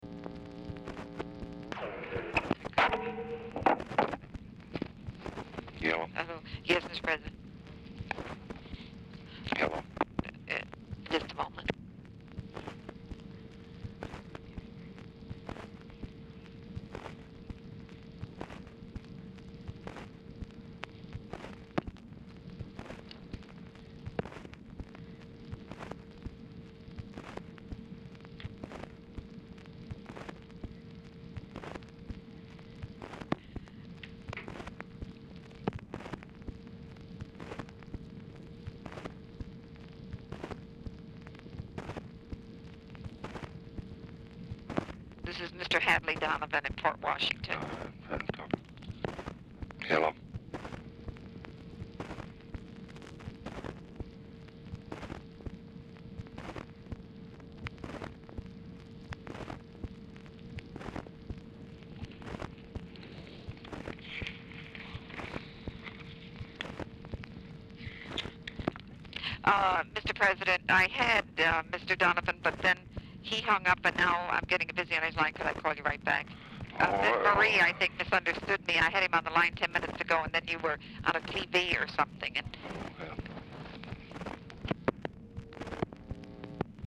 Telephone conversation # 5835, sound recording, LBJ and TELEPHONE OPERATOR, 10/3/1964, time unknown | Discover LBJ
LBJ ON HOLD THROUGHOUT MOST OF CALL
Format Dictation belt